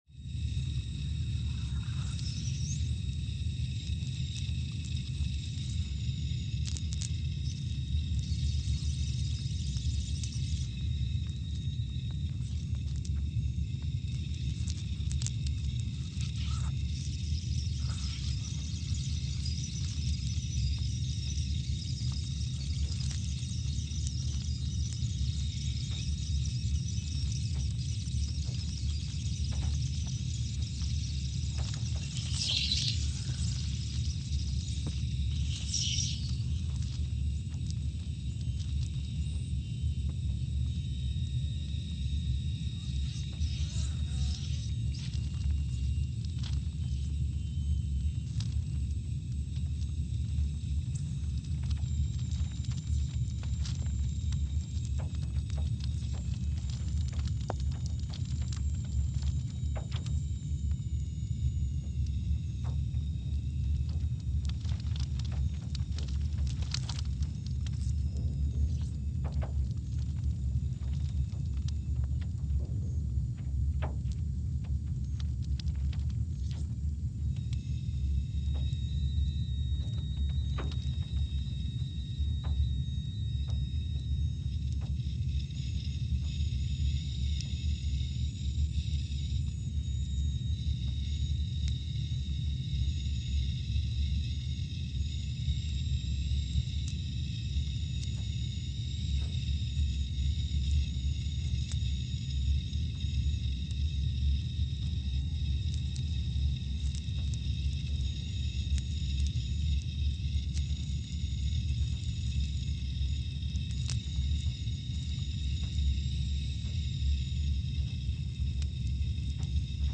Scott Base, Antarctica (seismic) archived on July 30, 2020
Station : SBA (network: IRIS/USGS) at Scott Base, Antarctica
Speedup : ×500 (transposed up about 9 octaves)
Gain correction : 25dB
SoX post-processing : highpass -2 90 highpass -2 90